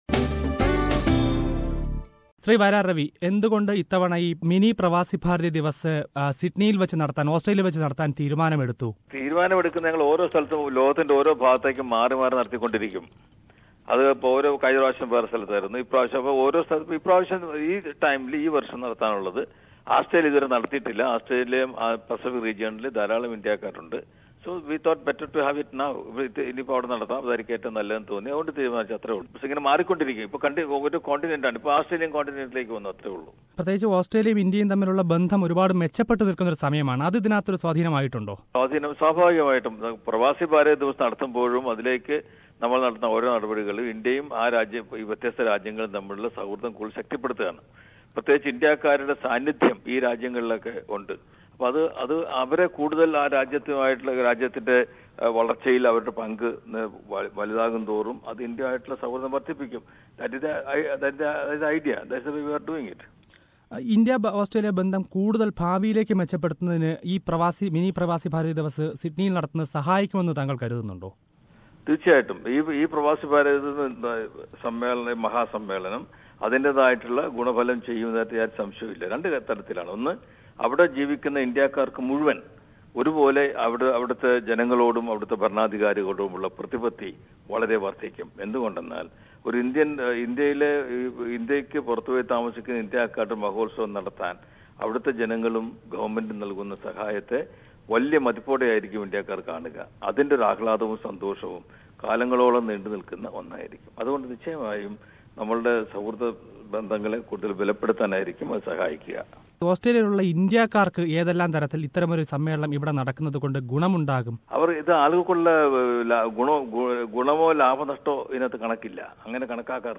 This year's Mini Pravasi Bharatiya Divas - a conference by the Indian government for Non-Resident Indians - will be held in Sydney in November. Indian union cabinet minister for Overseas Indian Affairs, Mr. Vayalar Ravi talks to SBS Malayalam